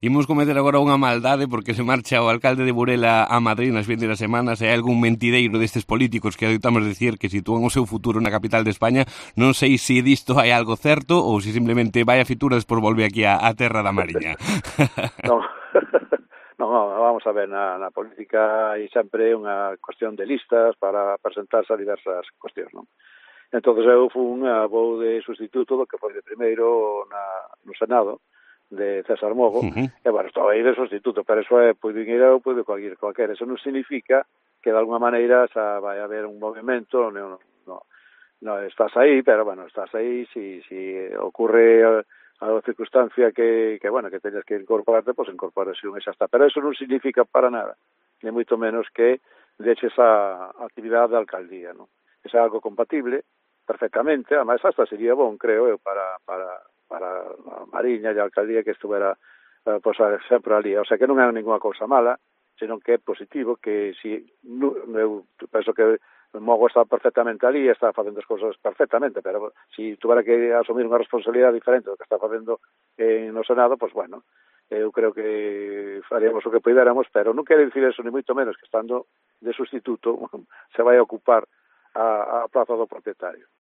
El alcalde de Burela ha manifestado en declaraciones a COPE de la Costa que no dejaría su actual cargo en el ayuntamiento